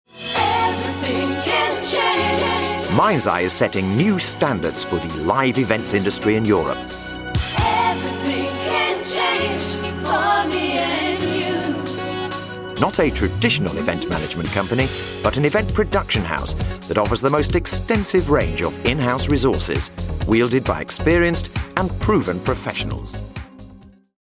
Corporate
Professional corporate male voiceover for Mindseye